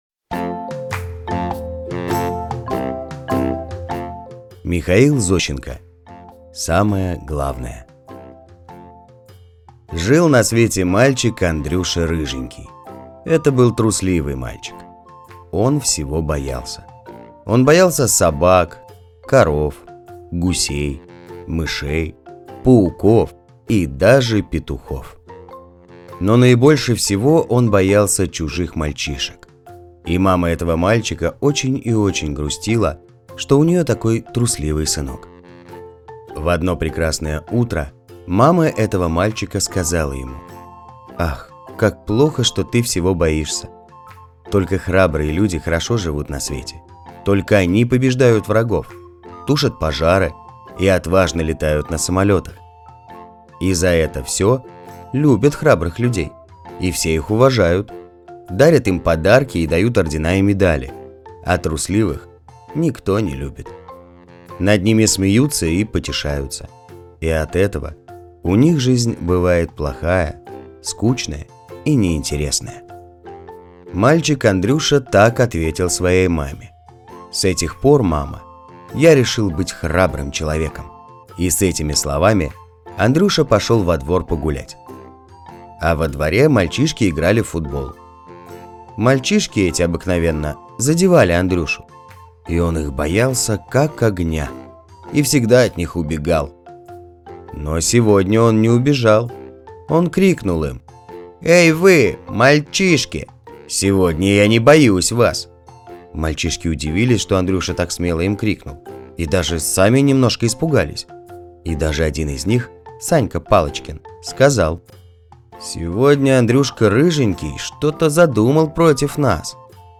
Аудиорассказ «Самое главное»